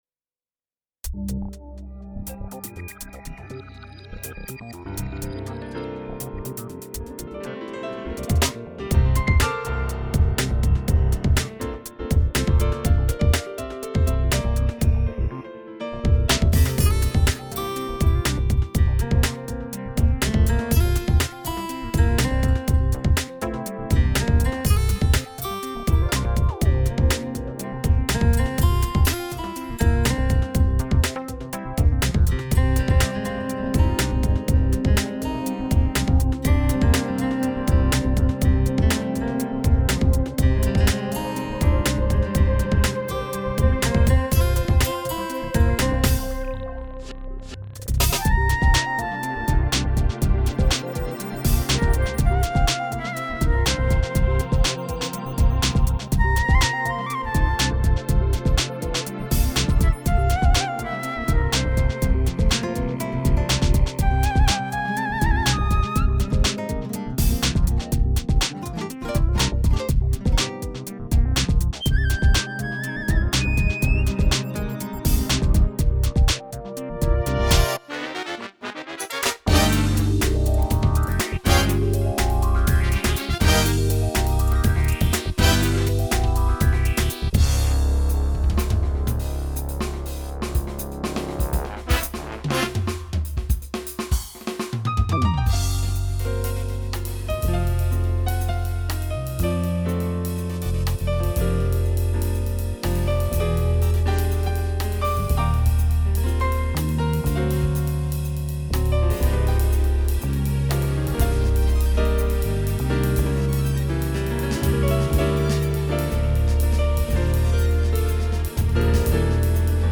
Genre: Dubstep